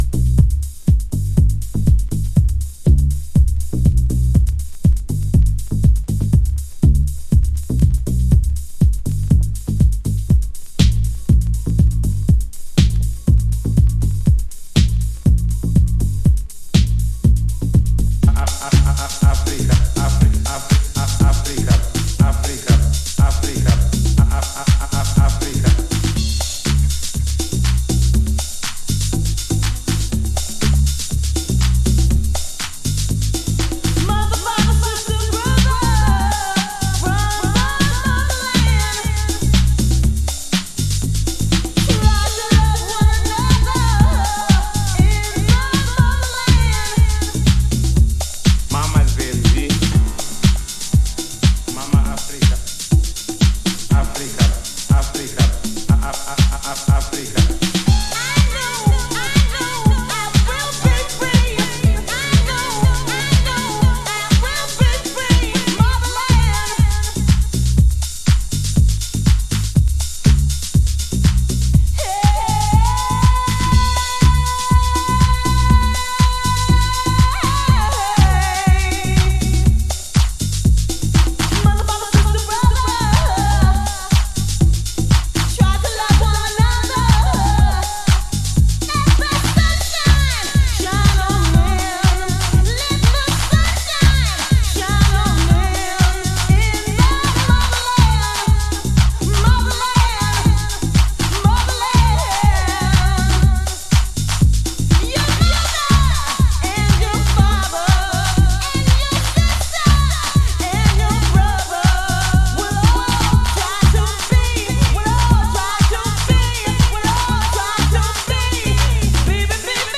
Early House / 90's Techno
マーシーナリーに表現されるアフロと当時のハウスの本気が伝わる熱量高いヴォーカルが滾るNY HOUSE代表曲。